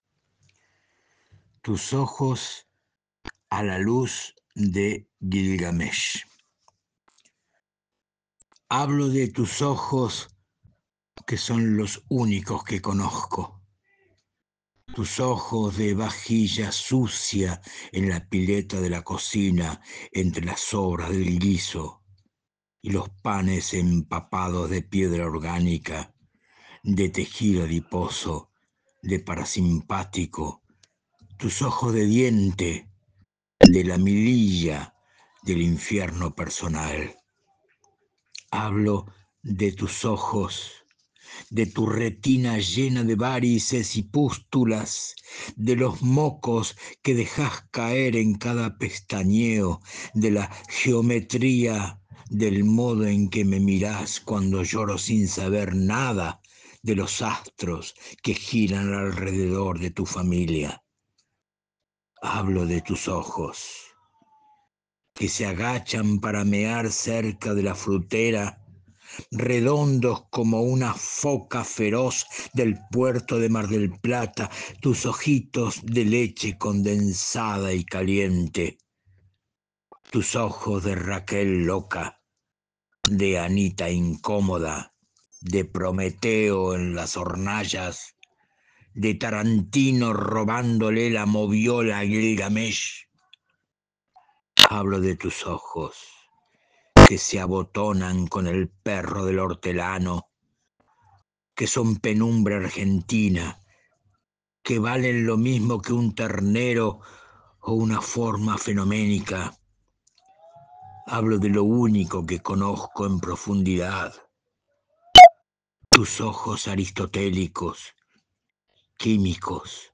Esperamos que disfruten de estas rarezas poéticas en su voz apasionada.